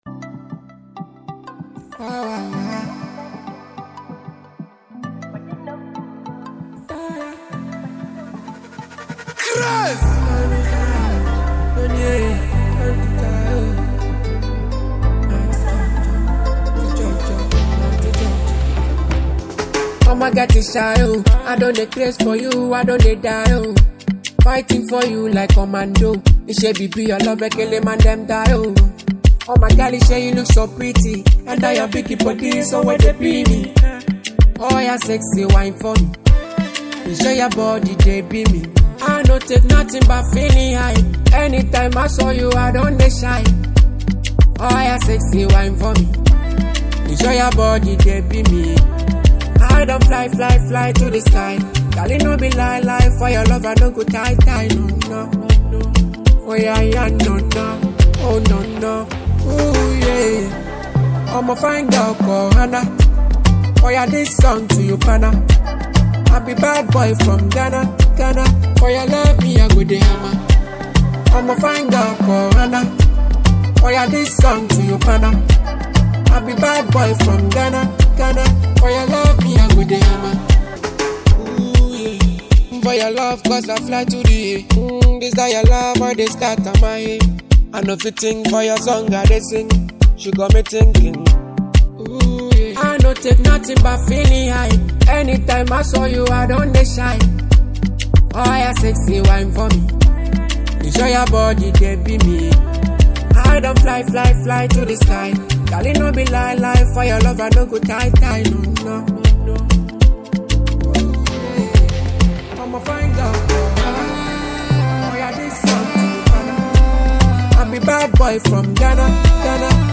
highlife
hot naija vibe